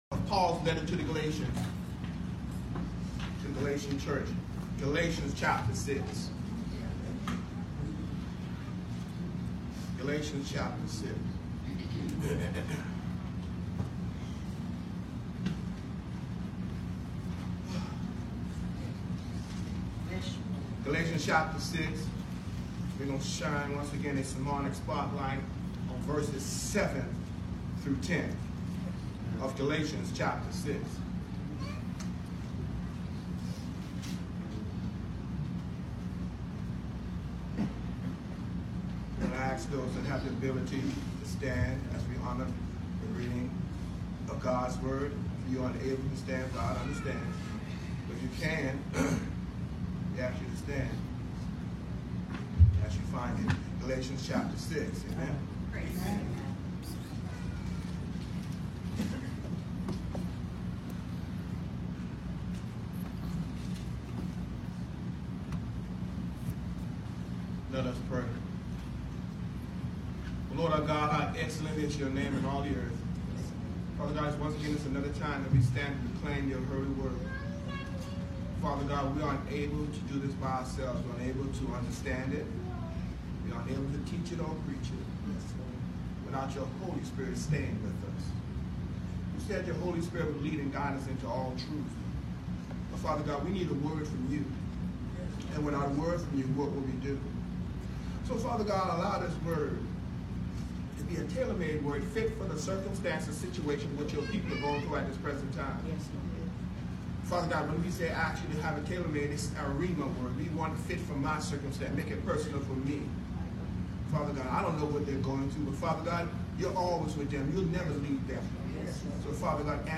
SEPTEMBER 22, 2019 SUNDAY 11:00am NEW JERUSALEM MB CHURCH GALATIANS 6:7-10 The Message: “I CAN’T QUIT” Part II